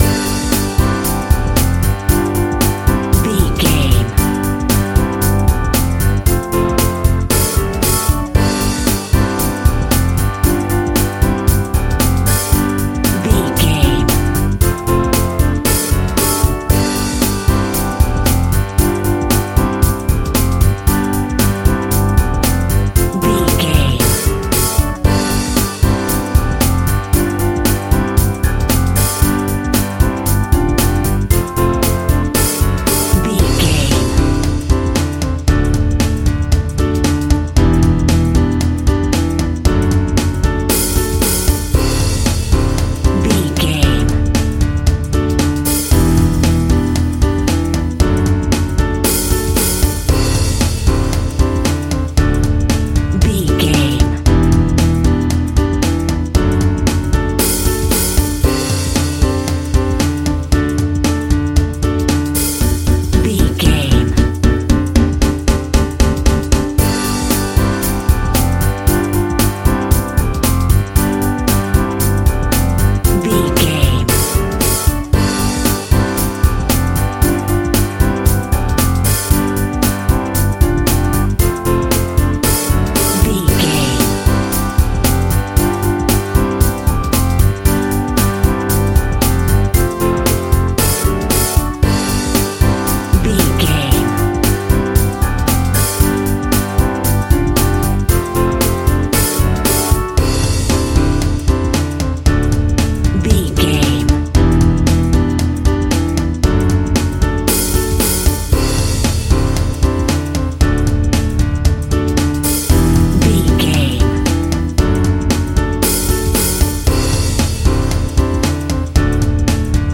Upbeat Pop.
Ionian/Major
pop rock
Bubblegum pop
cheesy
electronic pop
dance pop
pop instrumentals
light
drums
bass
keyboards
guitars